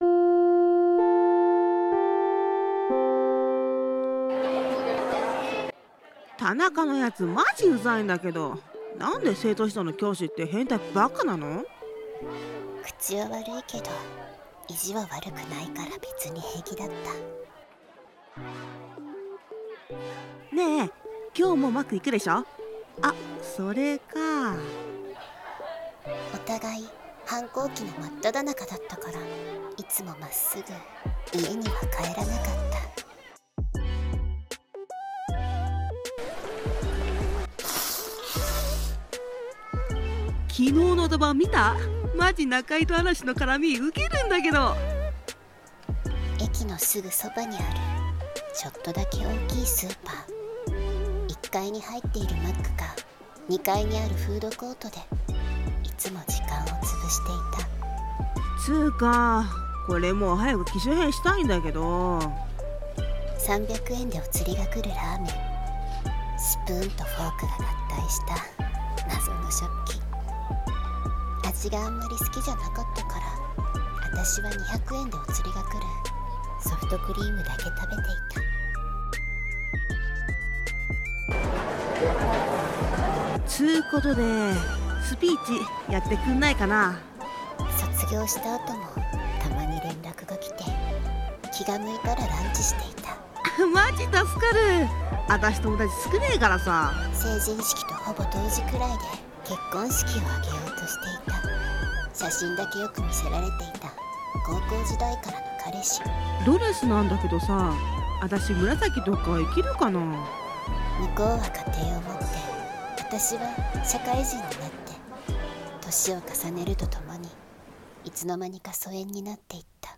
2人声劇